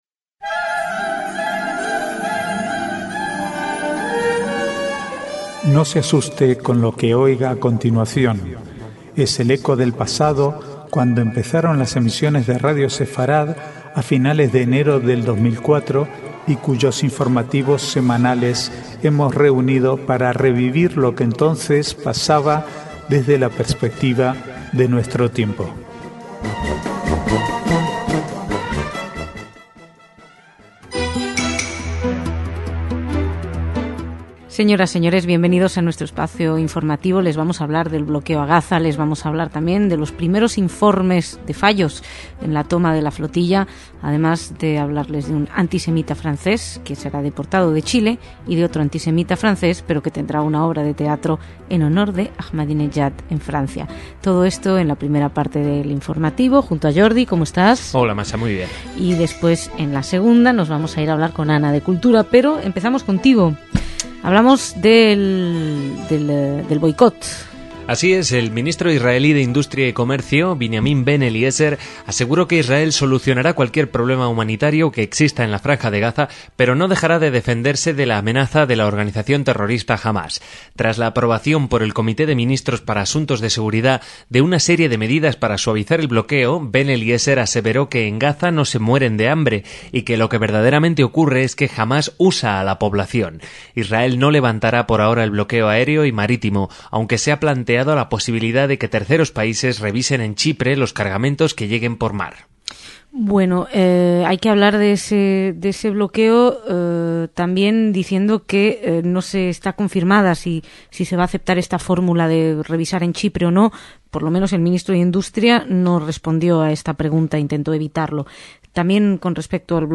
Archivo de noticias del 22 al 25/6/2010